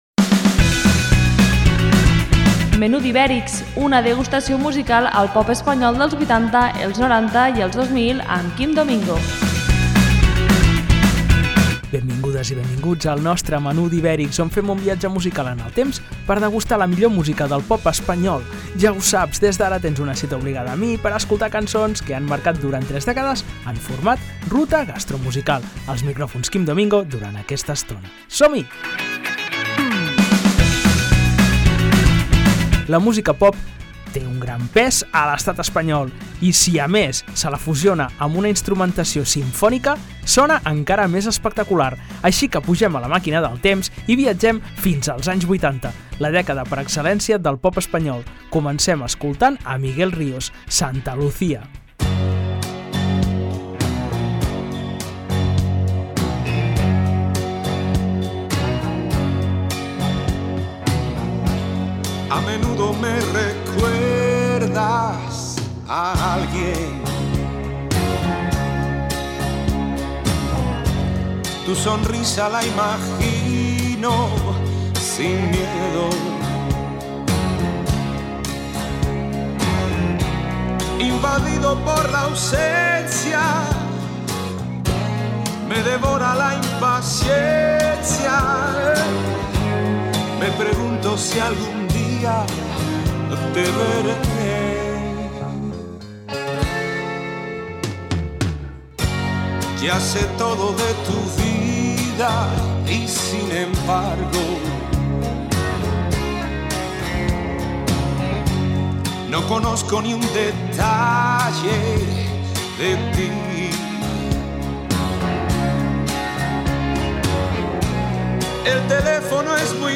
En esta ocasió, escoltem cançons acompanyades d’una orquestra simfònica.